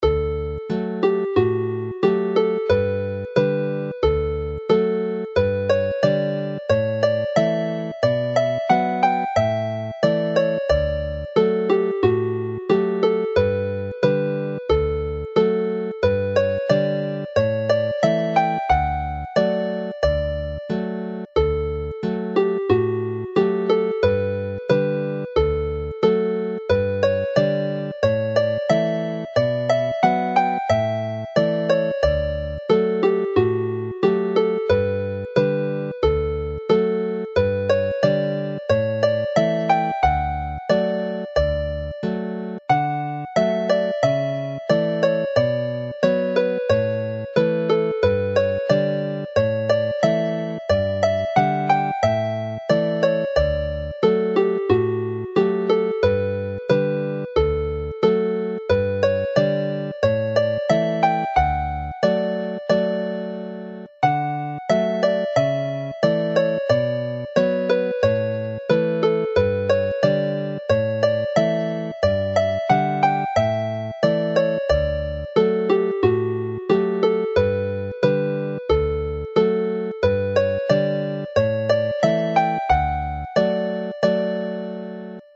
Play slowly